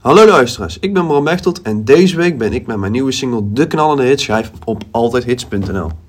Het liedje met een hoog feestgehalte is geschreven door